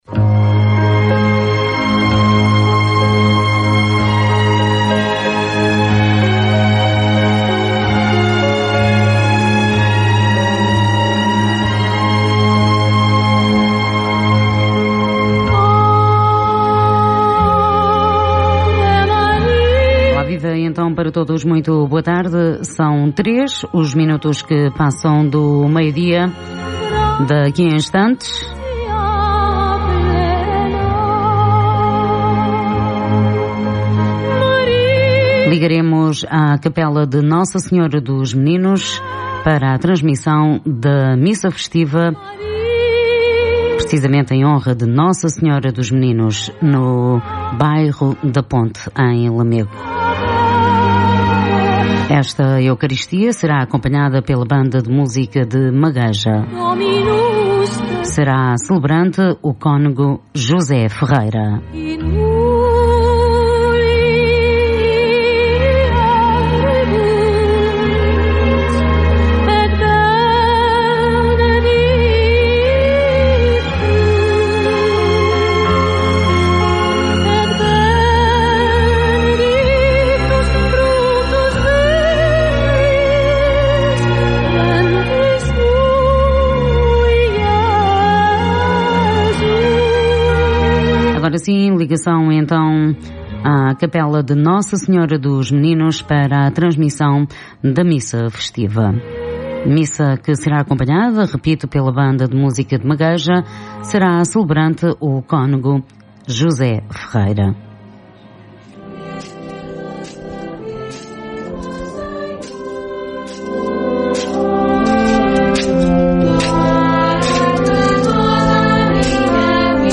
A Rádio Clube de Lamego transmitiu no dia 21 de Setembro a Eucaristia Festiva em Honra de Nossa Senhora dos Meninos diretamente do Bairro da Ponte, na cidade de Lamego.